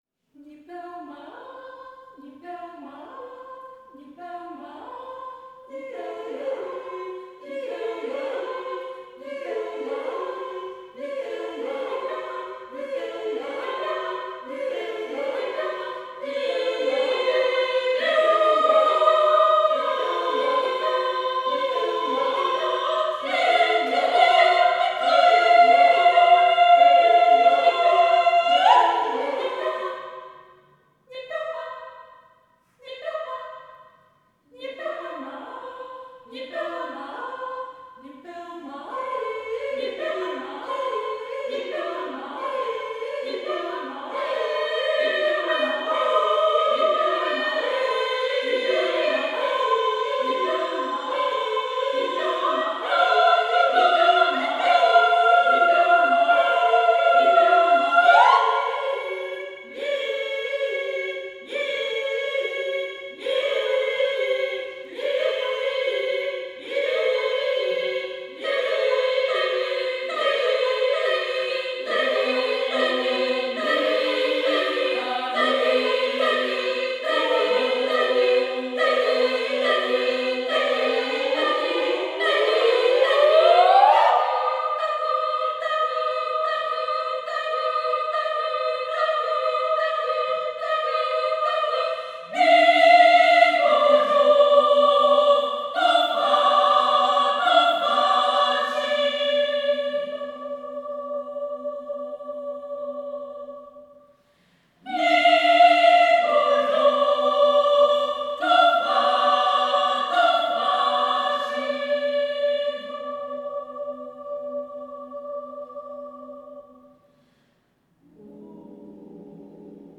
Música vocal
Música tradicional